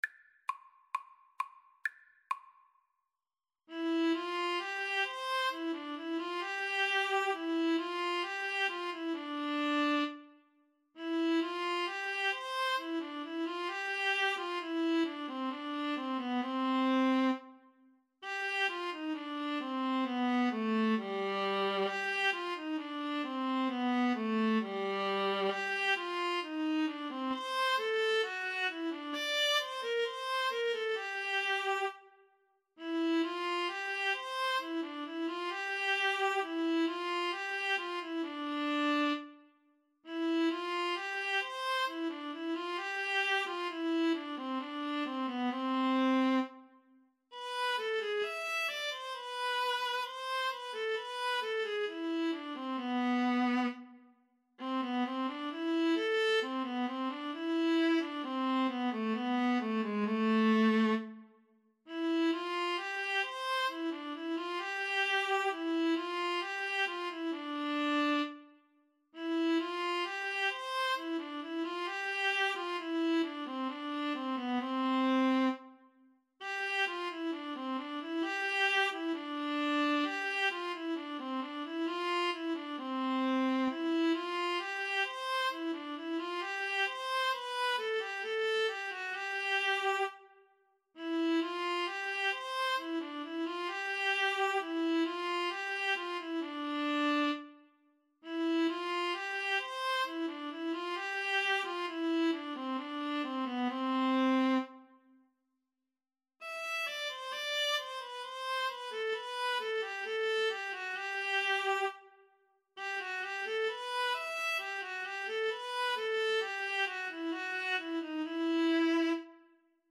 2/2 (View more 2/2 Music)
~ = 100 Allegretto =c.66
Classical (View more Classical Violin-Viola Duet Music)